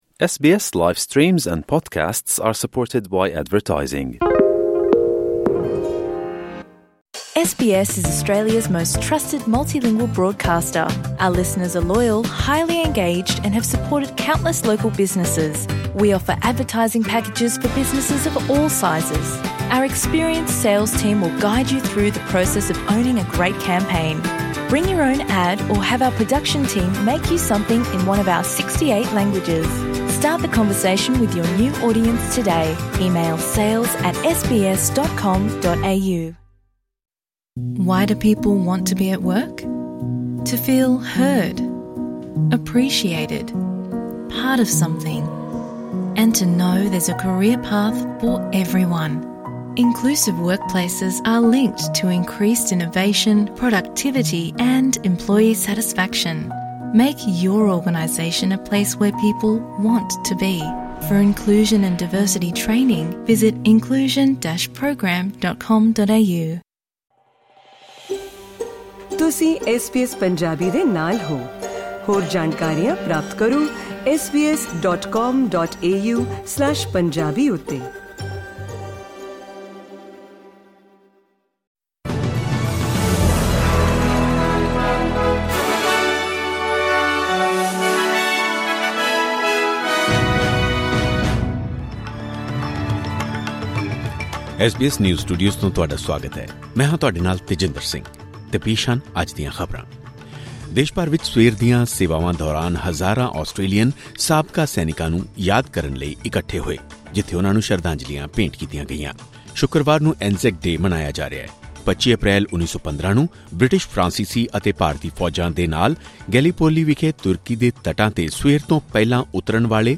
ਖਬਰਨਾਮਾ: ਆਸਟ੍ਰੇਲੀਆ ਭਰ ਵਿਚ ANZAC Day ਦੌਰਾਨ ਕੀਤਾ ਗਿਆ ਸਾਬਕਾ ਸੈਨਿਕਾਂ ਨੂੰ ਯਾਦ